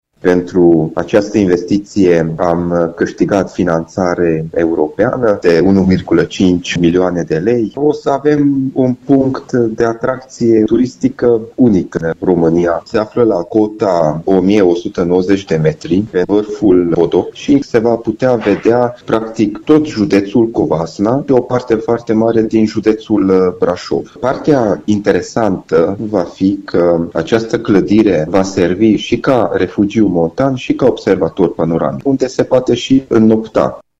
Vicepreședintele CJ Covasna, Jakab Istvan Barna: